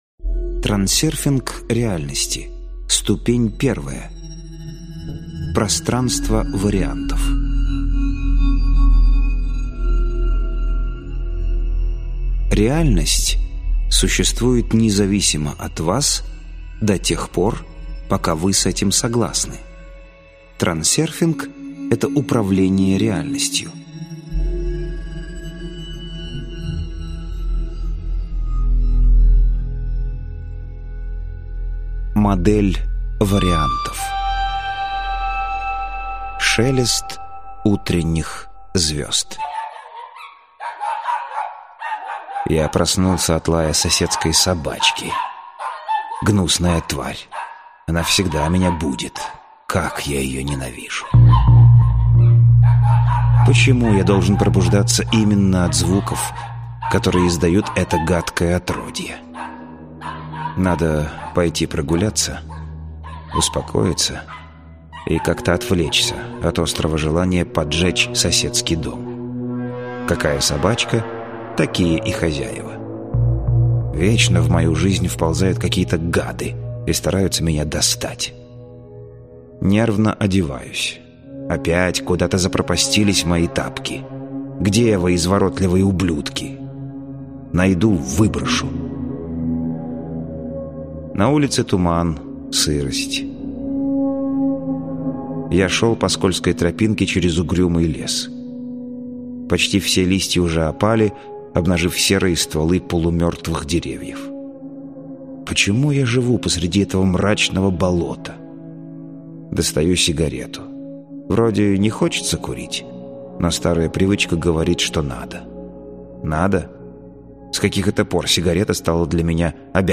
Аудиокнига Трансерфинг реальности. Ступень I: Пространство вариантов - купить, скачать и слушать онлайн | КнигоПоиск